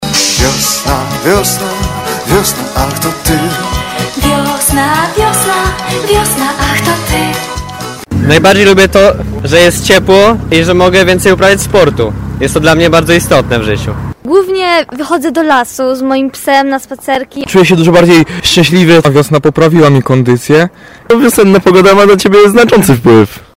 Tagi: Tarnów pogoda wiosna sonda pierwszy dzień wiosny dzień wagarowicza